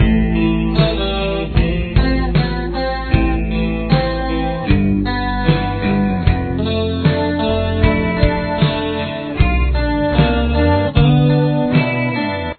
Verse Riff